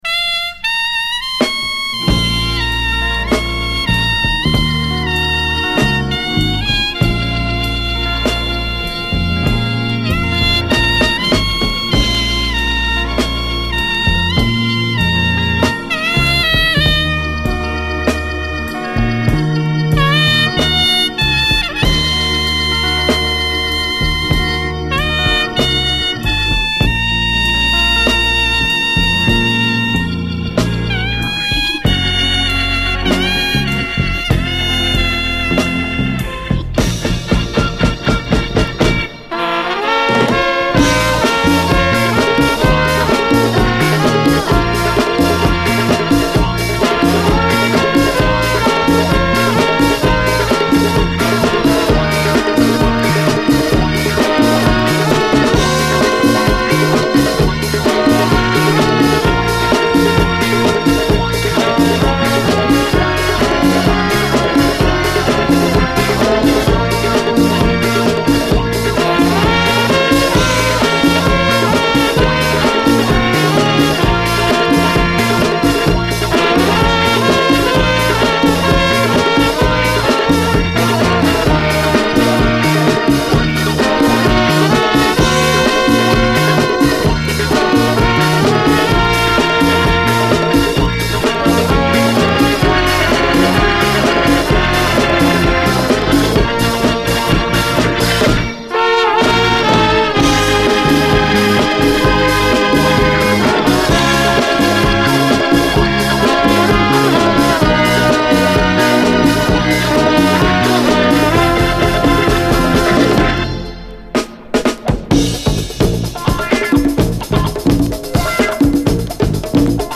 SOUL, 70's～ SOUL, DISCO, LATIN
内容最高、悶絶必至のモンスター・ラテン・ディスコ〜ラテン・ファンク・ボム！
マイアミのB級ラテン・ファンク・バンドが起こした奇跡！